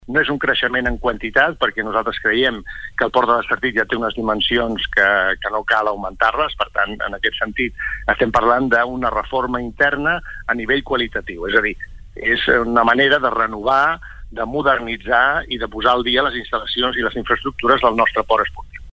Entrevista sencera